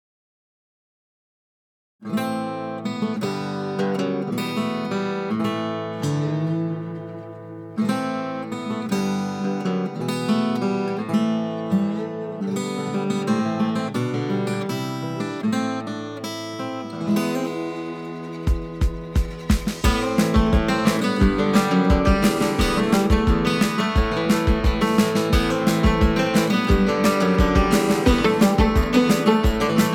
Струнные и рояль
Жанр: Классика